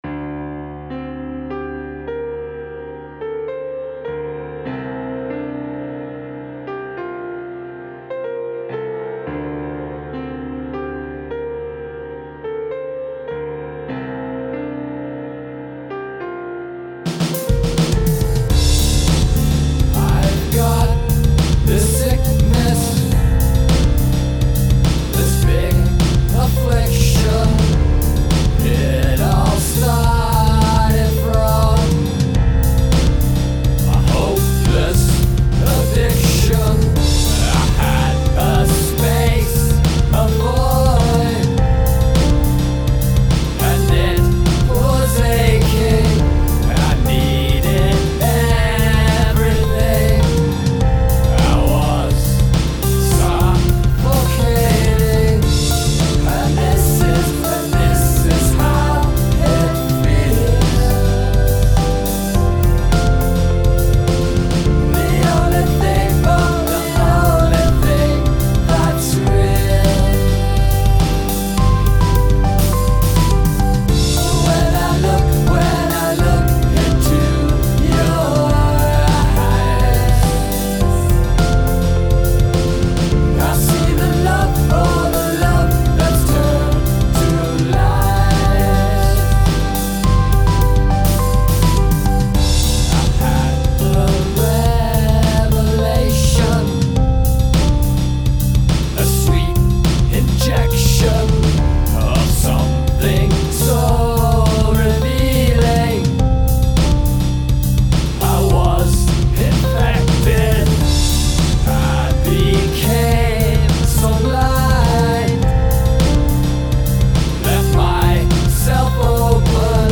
GENRE - Rock - Various Styles